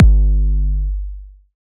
EDM Kick 30.wav